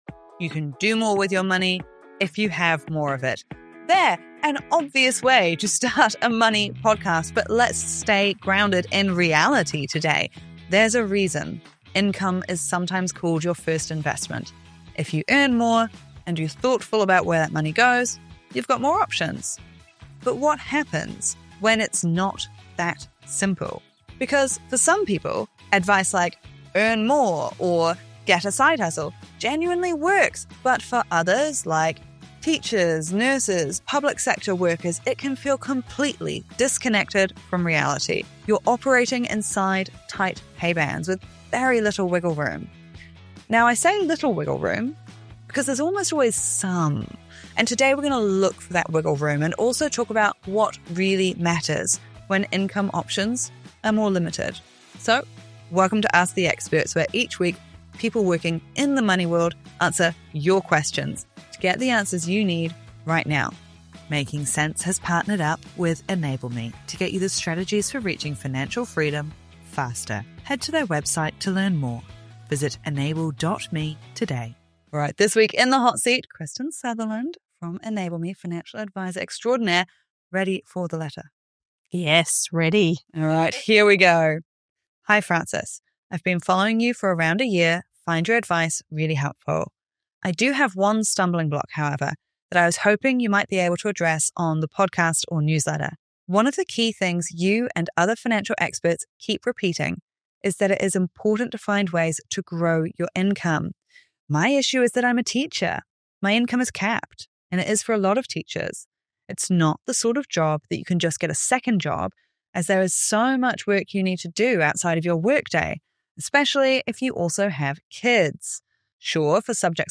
In this Ask the Experts mailbag episode of Making Cents, we tackle a question that so many teachers, nurses, and public sector workers quietly wrestle with: what do you do when your income is capped?